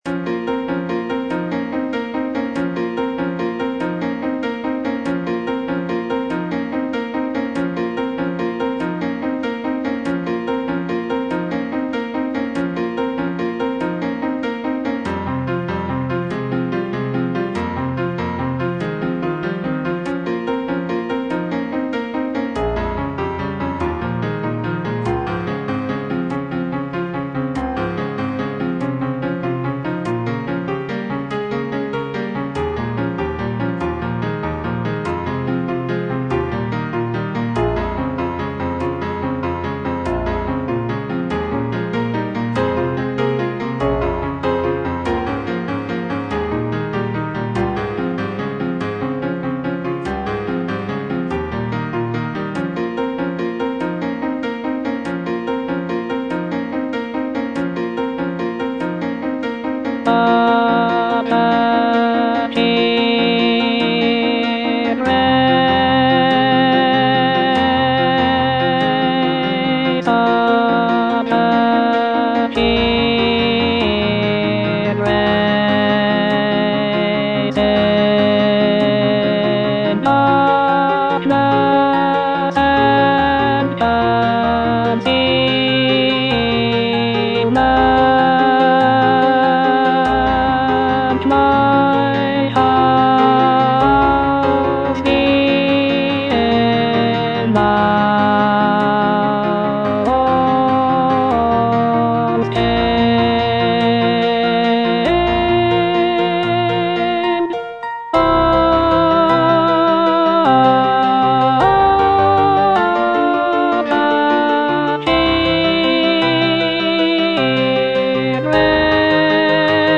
alto II) (Voice with metronome) Ads stop